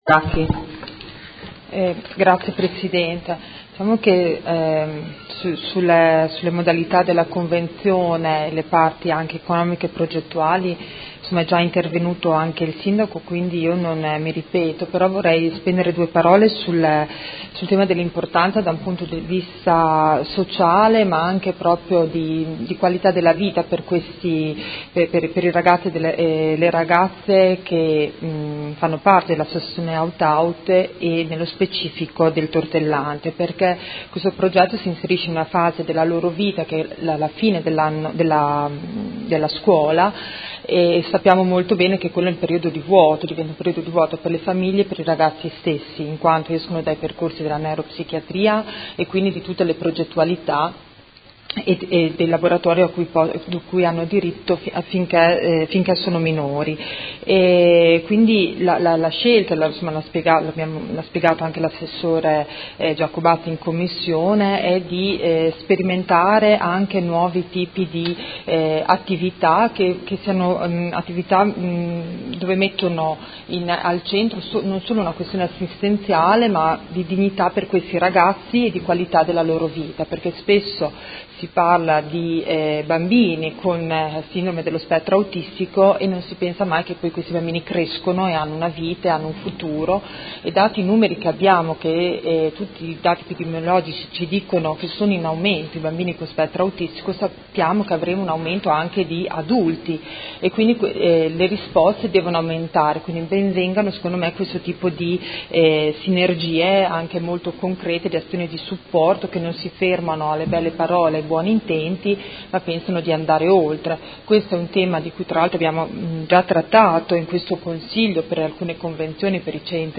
Seduta del 23/11/2017 Dibattito.